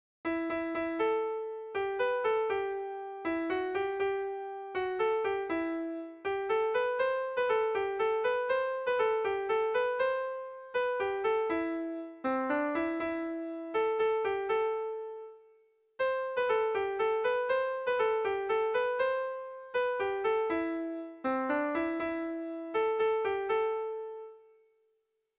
Erlijiozkoa
Bostekoa, berdinaren moldekoa, 4 puntuz (hg) / Lau puntukoa, berdinaren moldekoa (ip)
ABDE